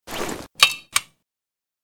vz61_draw.ogg